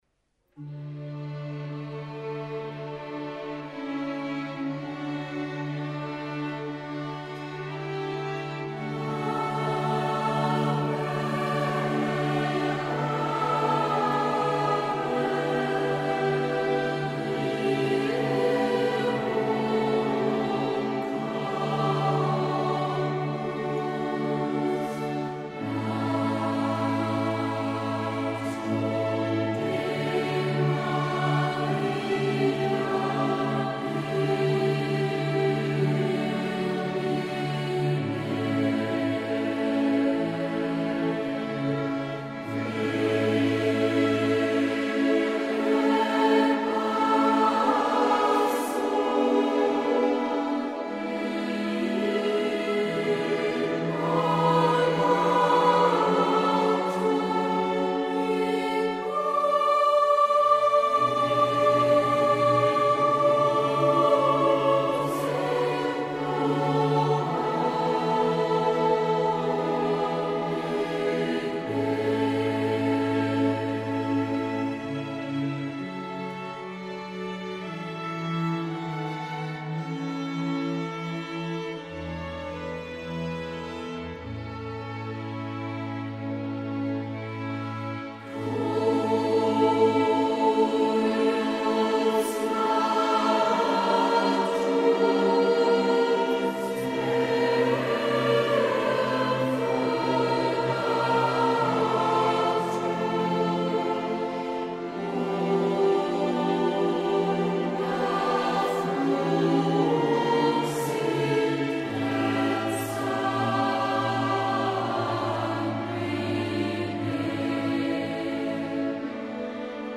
in der Entenfußhalle des Klosters Maulbronn
für Chor und Streichorchester